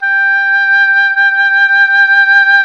WND OBOE2 0B.wav